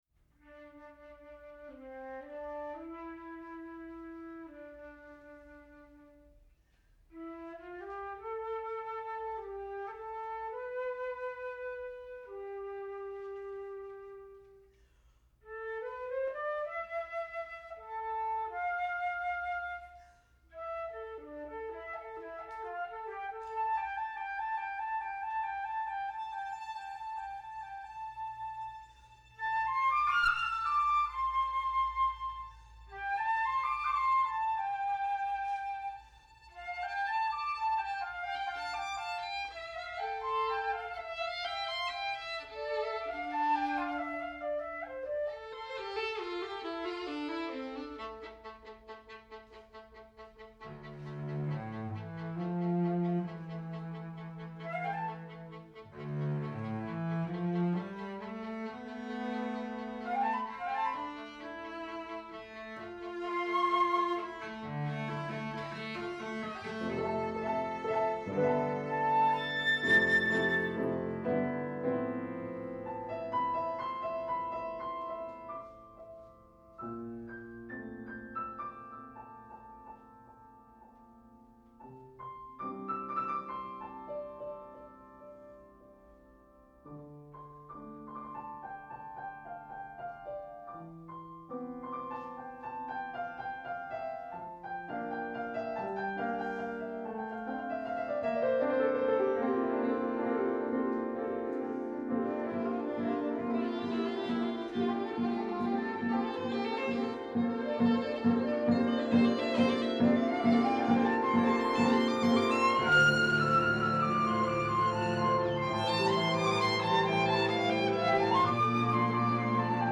flautist
violin
cello
Piano
for Flute doubling Alto Flute/Piccolo and Piano Trio